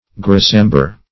Grisamber \Gris"am`ber\, n.
grisamber.mp3